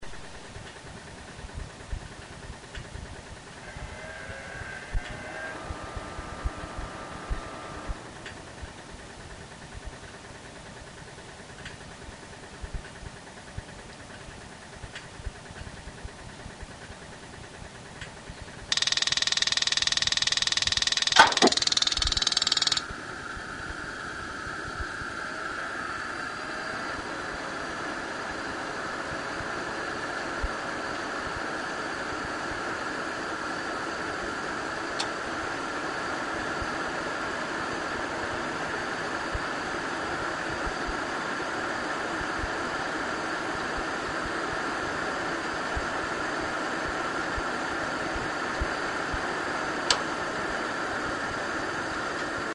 点火テスト。
OH-J40R点火時の音　50秒